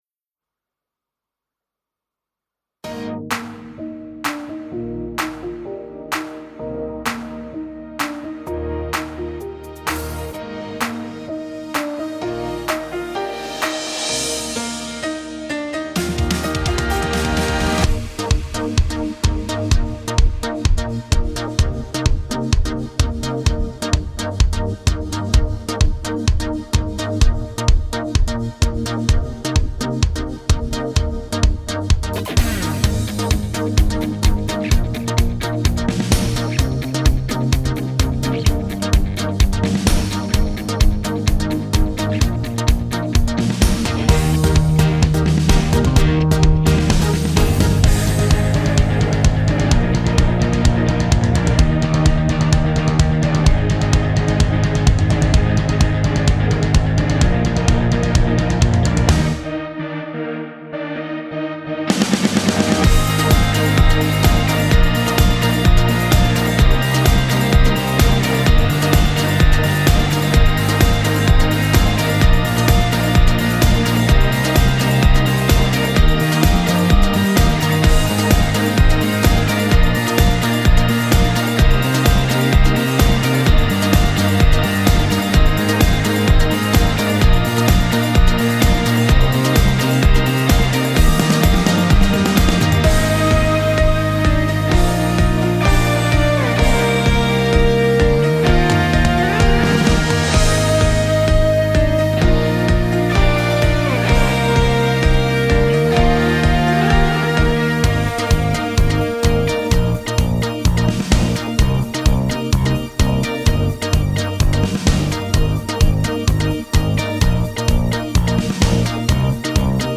楽曲（カラオケVer.）